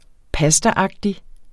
Udtale [ ˈpasdaˌɑgdi ]